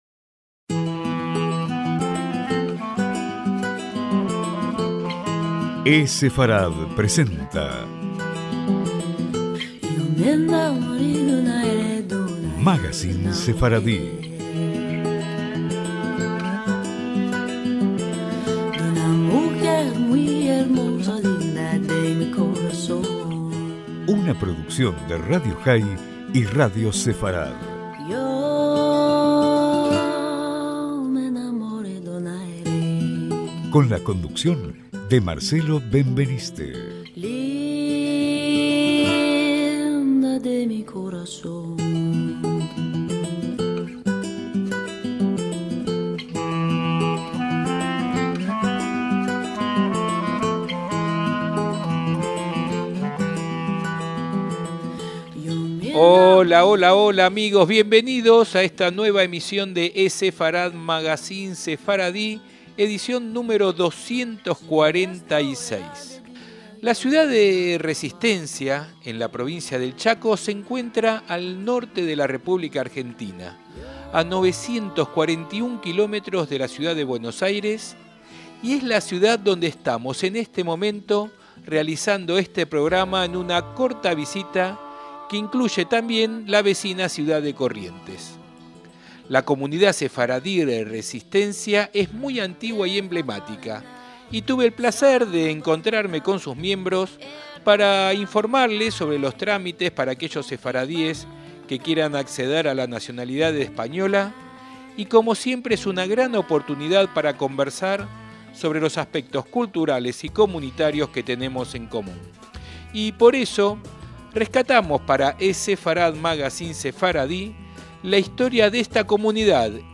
ESEFARAD: MAGACÍN SEFARDÍ - Programa realizado en la Ciudad de Resistencia en la Provincia del Chaco, al norte de la República Argentina, a 941 km de Buenos Aires.